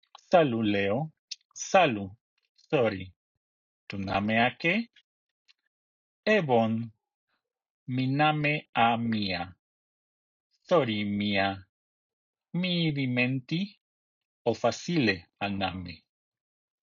Let’s see a quick conversation with these: